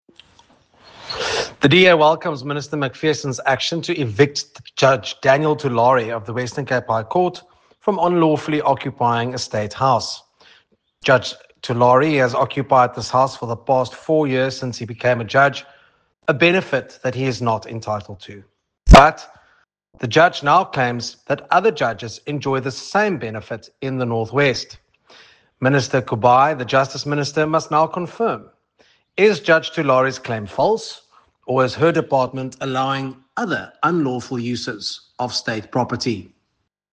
Attention Broadcasters: Soundbites in